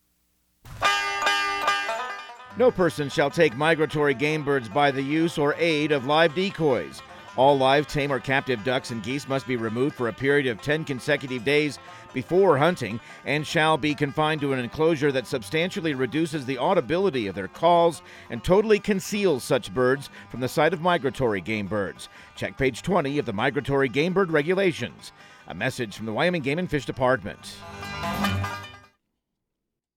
Outdoor Tip/PSA